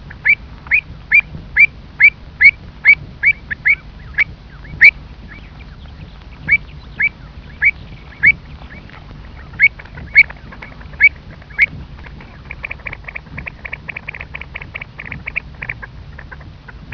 Кулик-сорока – Haematopus ostralegus osculans Swinhoe , 1871 Отряд Ржанкообразные – Charadriformes Семейство Кулики - сороки – Haematopodidae Статус : III категория.